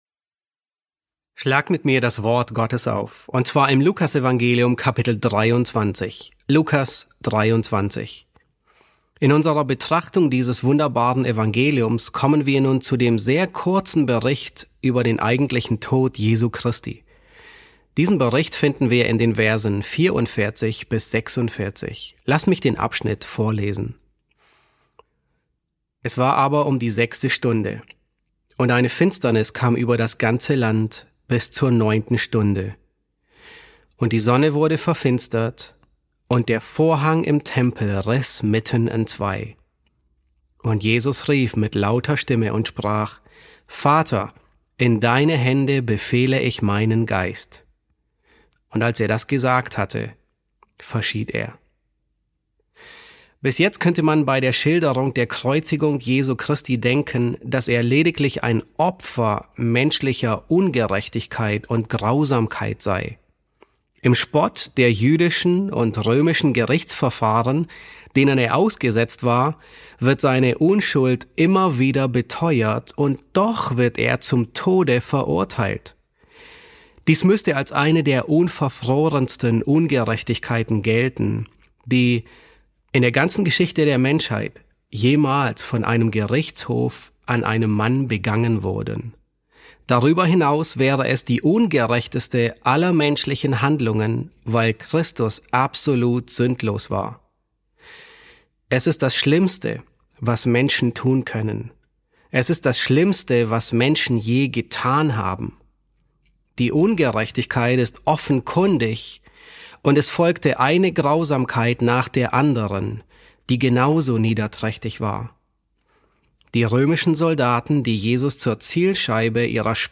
E4 S1 | Der gekreuzigte König: Vollendung auf Golgatha ~ John MacArthur Predigten auf Deutsch Podcast